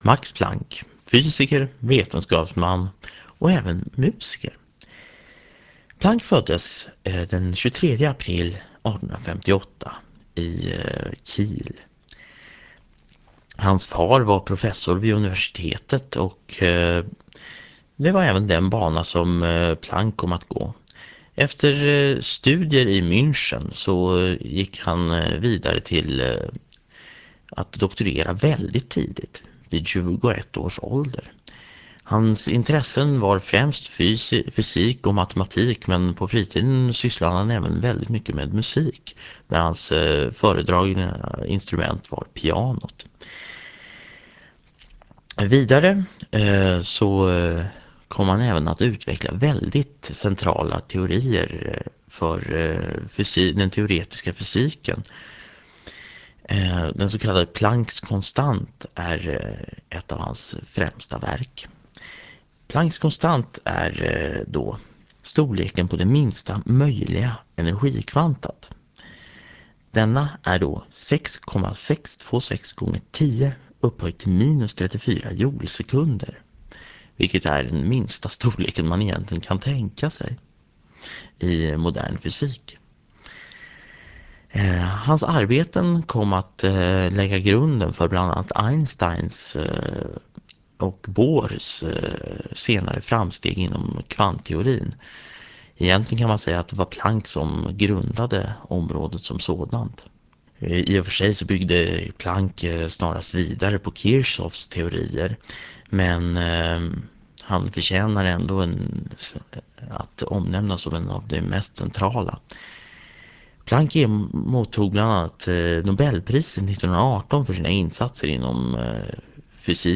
Föredraget handlar om Fysik och sänds 2002-04-14 i Förklarade_fenomen.